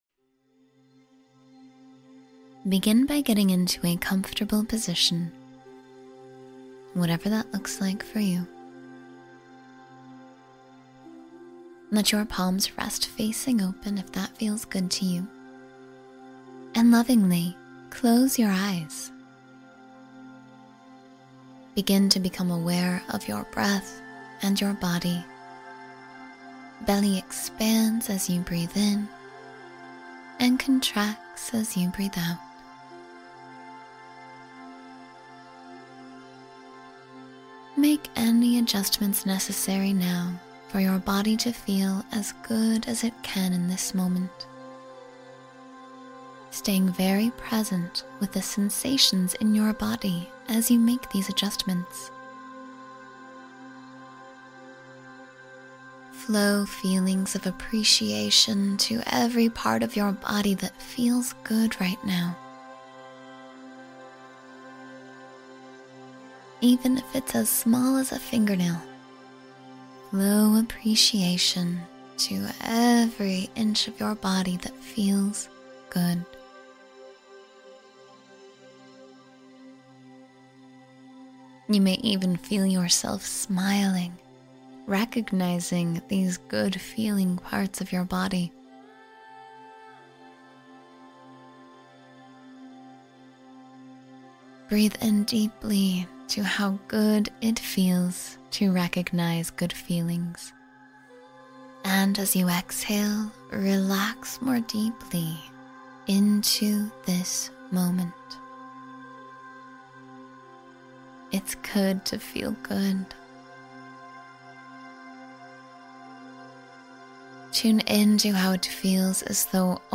New Moon Meditation for Renewal — Start Fresh and Aligned with the Universe